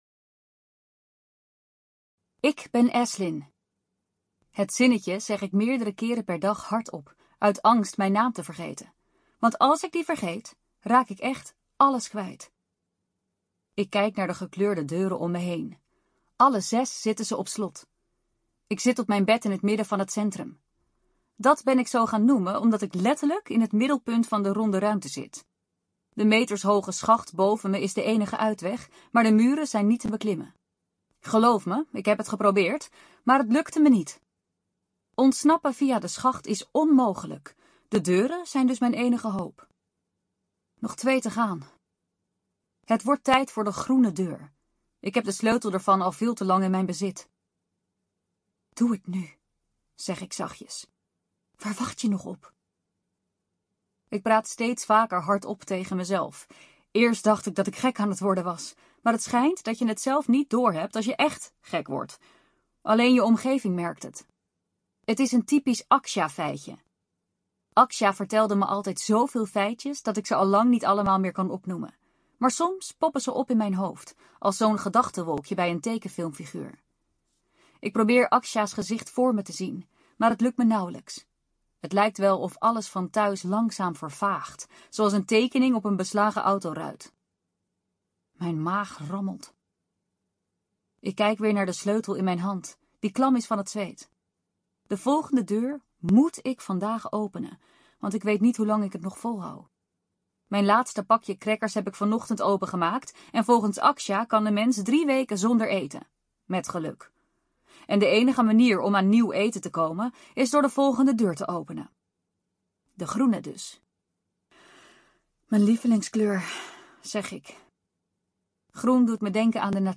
Afterpartyspotifyaudioboek.mp3